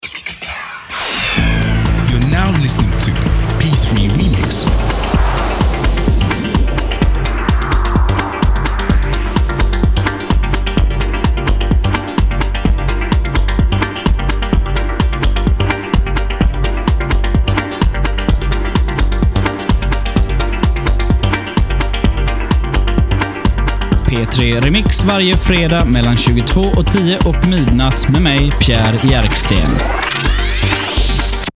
Här nedan hör du lite reklam för programmen.